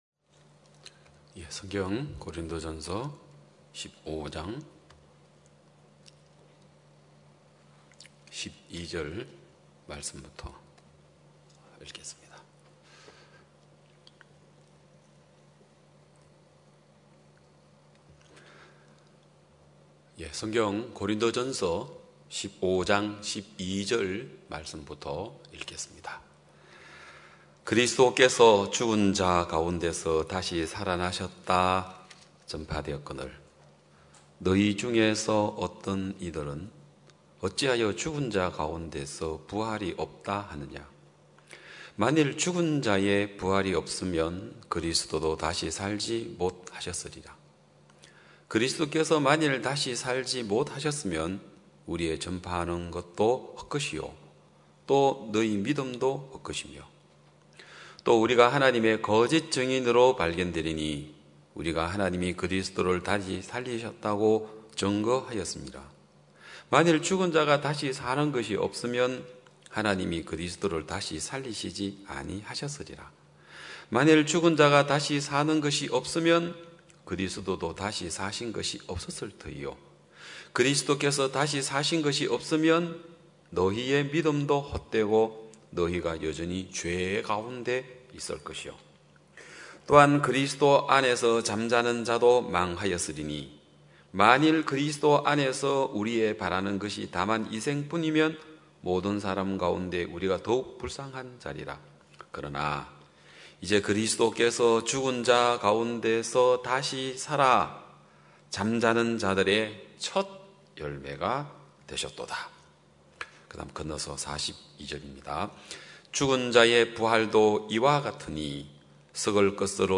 2021년 9월 19일 기쁜소식양천교회 주일오전예배
성도들이 모두 교회에 모여 말씀을 듣는 주일 예배의 설교는, 한 주간 우리 마음을 채웠던 생각을 내려두고 하나님의 말씀으로 가득 채우는 시간입니다.